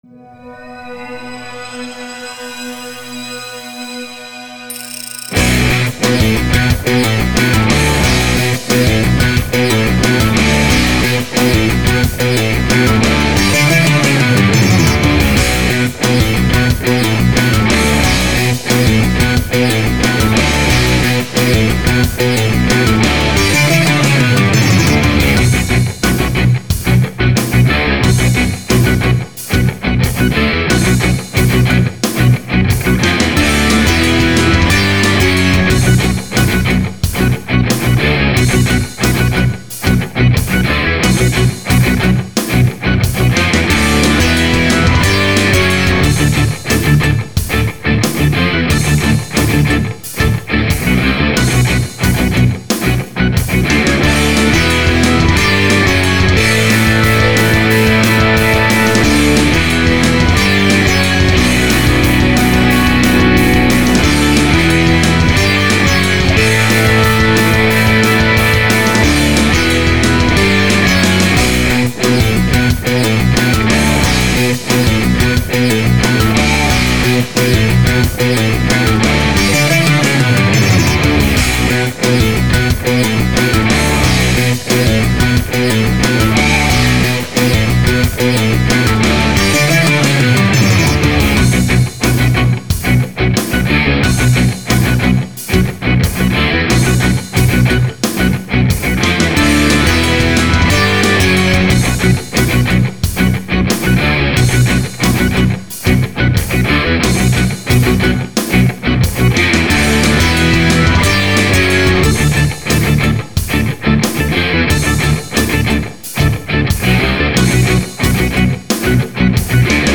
Instrumental, Metal
This is the karaoke (off-vocal) version of a song I wrote some time ago.
For now just enjoy it as quite heavy instrumentalÂ  rock piece with some weird measure changes in there 🙂